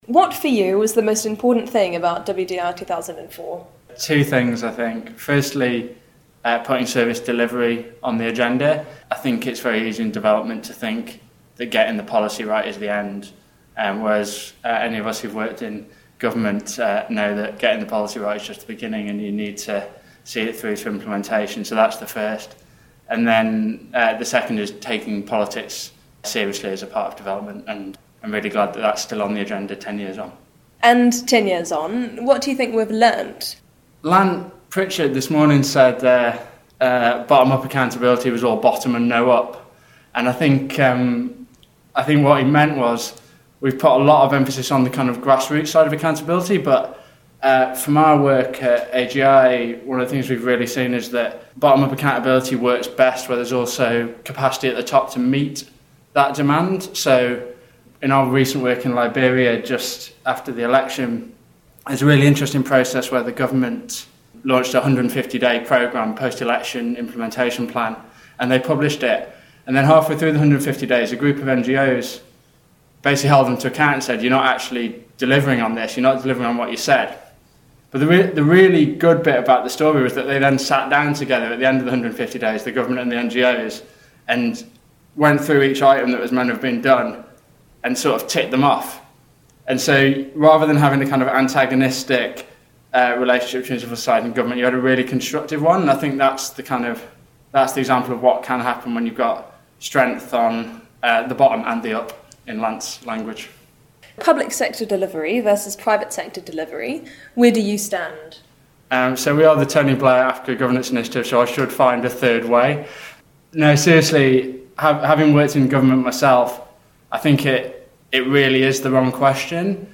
At the conference for the 10th anniversary of the World Development Report 2004